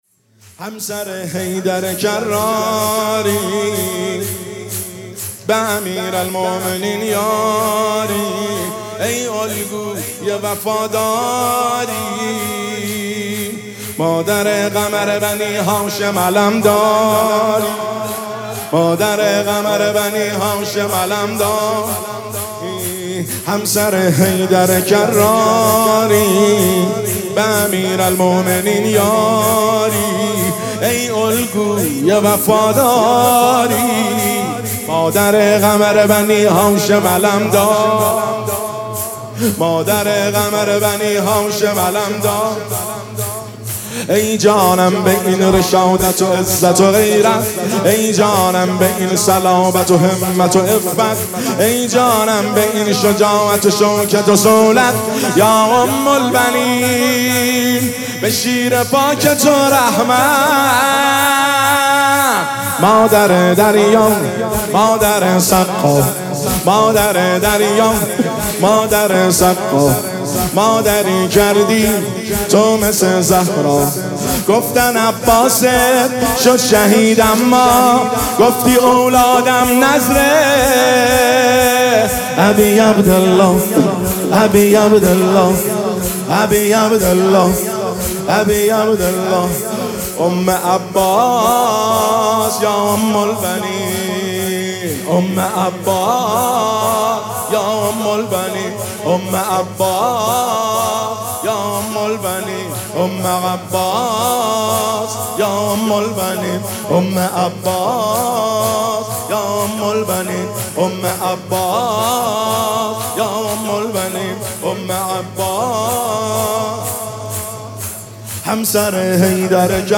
شور – شب وفات حضرت اُم البنین (س) 1402 هیئت مجانین الحسین تهران